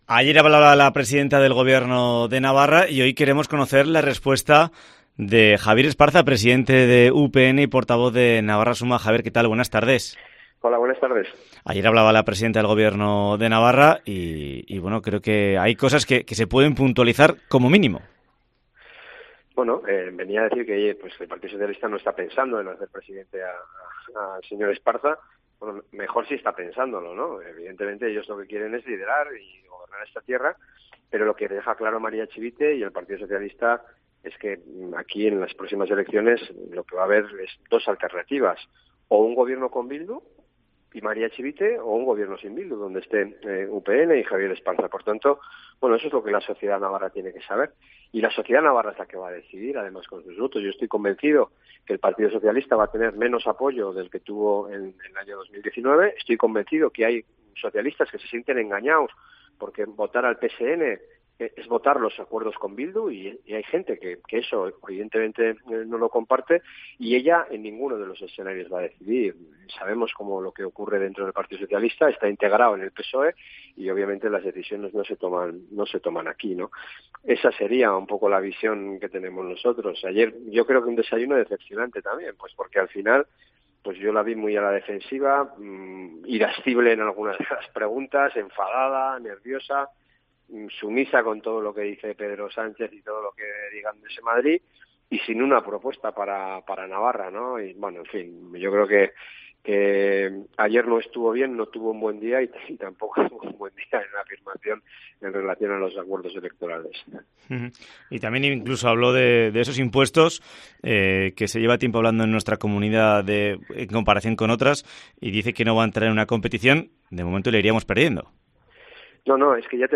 Entrevista a Javier Esparza en COPE Navarra